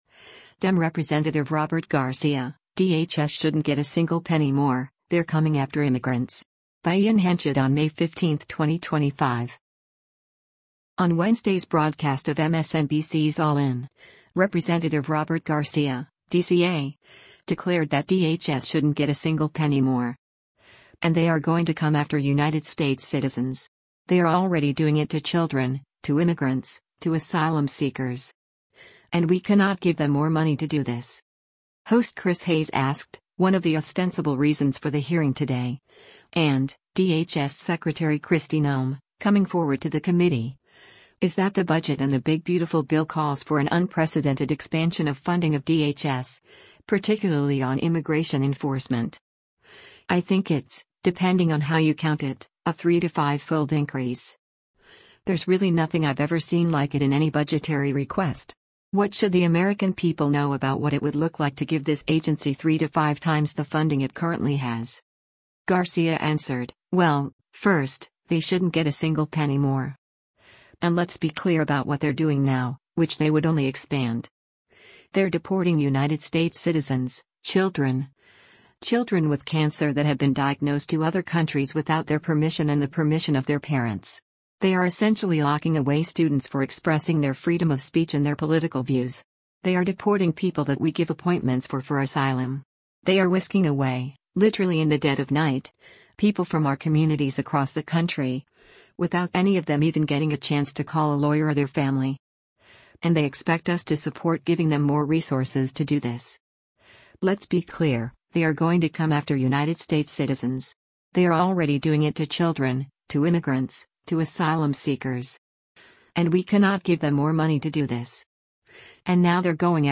Video Source: MSNBC
On Wednesday’s broadcast of MSNBC’s “All In,” Rep. Robert Garcia (D-CA) declared that DHS “shouldn’t get a single penny more.”